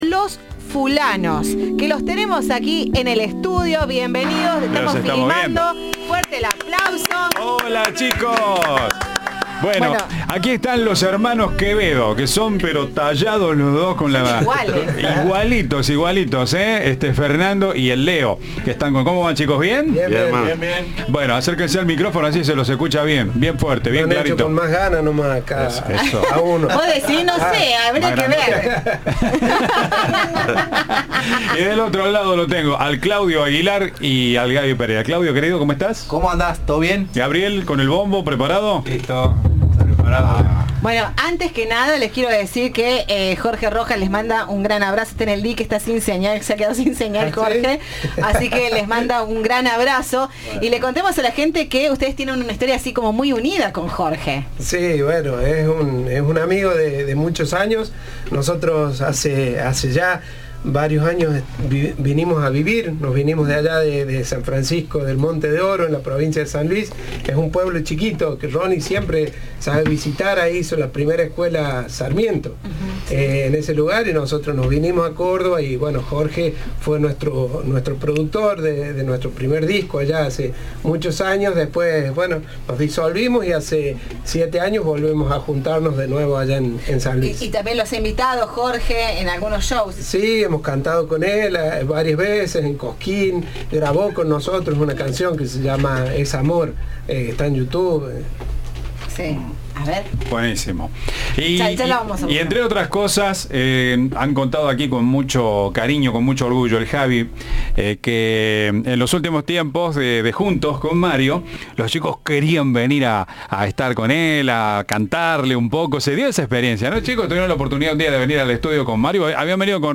El grupo folclórico se presenta este jueves a las 22 en el escenario de Cadena 3, en la supermanzana del Mercado Norte de la ciudad de Córdoba. Antes, sus integrantes visitaron Viva la Radio.